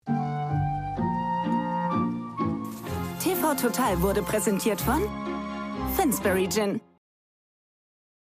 TVC: Sponsoring "TV Total" / Pro7
TVC_Sponsoring_TVTotal_Finsbury_Gin.mp3